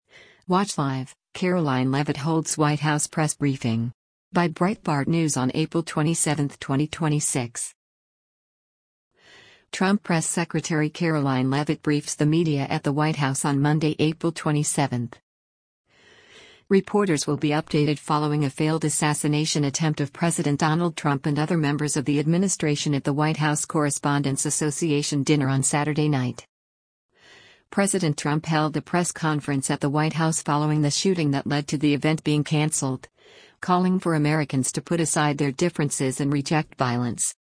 Trump Press Secretary Karoline Leavitt briefs the media at the White House on Monday, April 27.